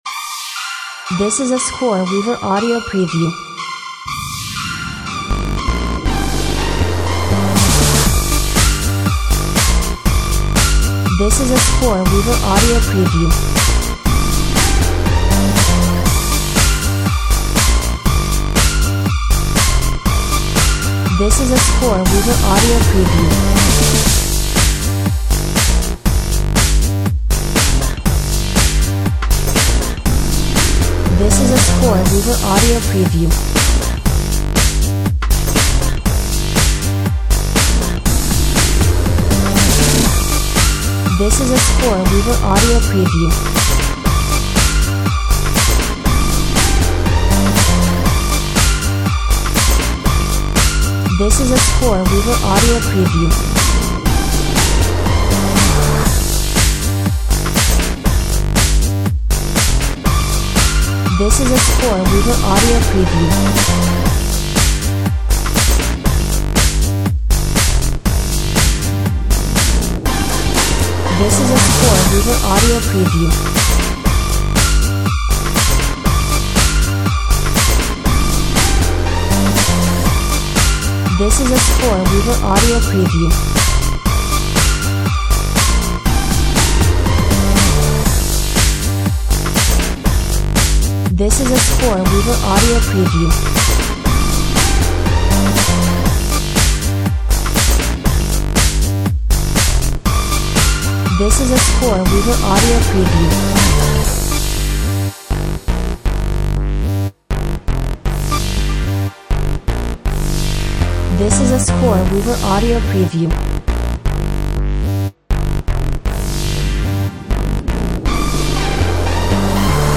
A real fun track that will keep the audience engaged.